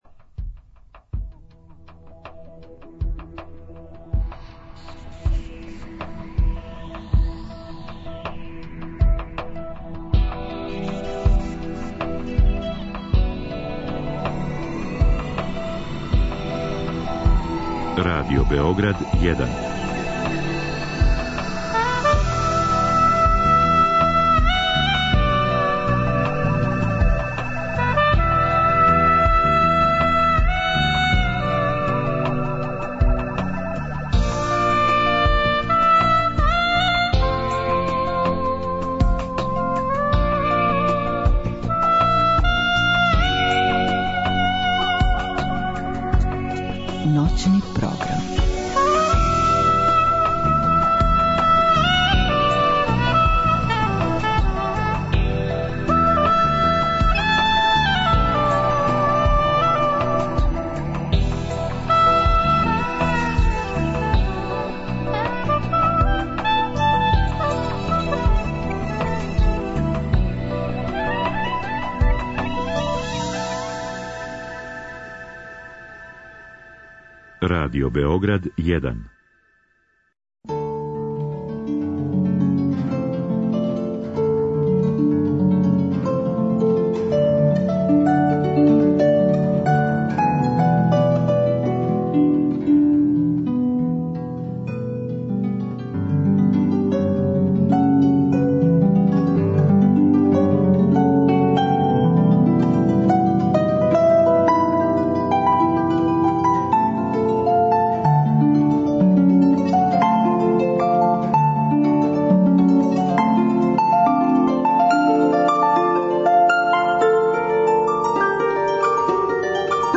Од два часа па до краја емисије слушамо композиције Албениза, Сенберга, Кромера и Риса.